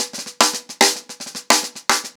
TheQuest-110BPM.19.wav